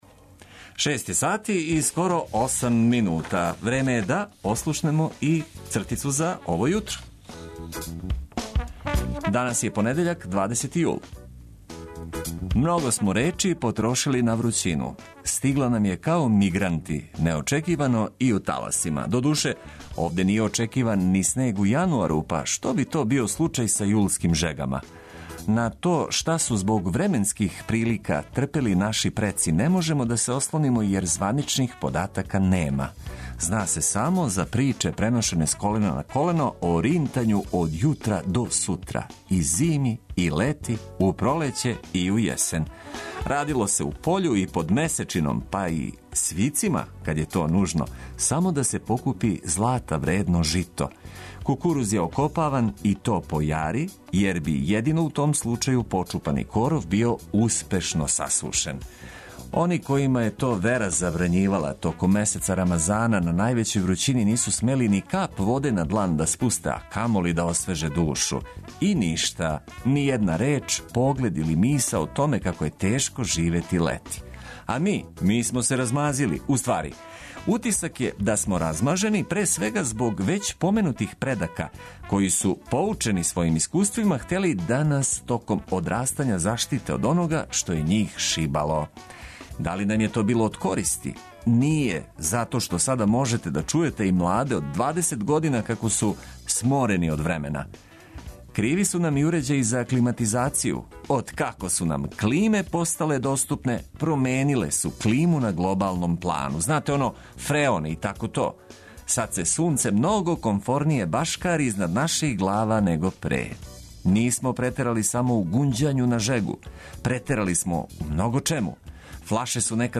Корисне информације уз обиље добре музике, то је одлика заједничког започињања новог дана, првог у седмици.